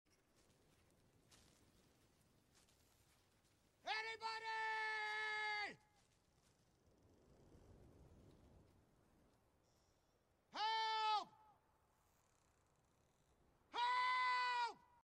The Voice Of Vegeta Maybe Sound Effects Free Download